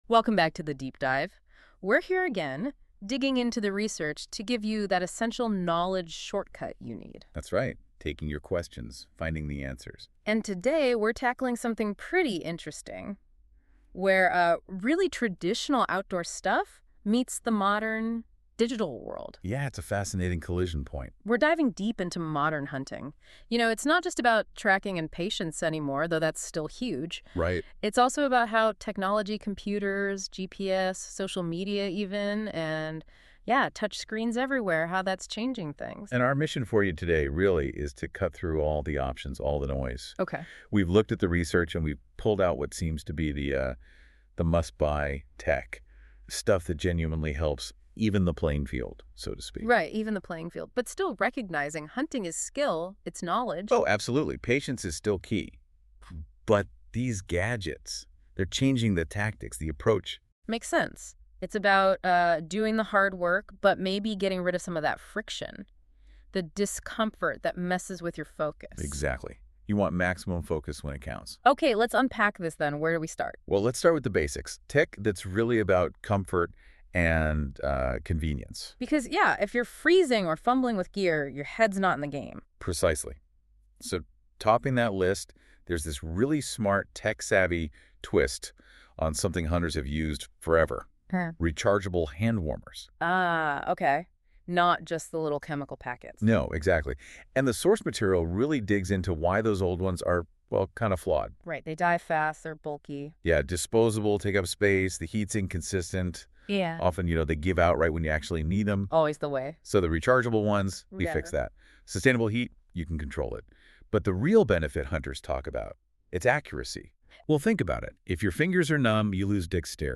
AI generated summary The source material, presented as a deep-dive analysis, examines the essential technological blueprint for the modern, hybrid hunter who merges traditional skill with digital efficiency.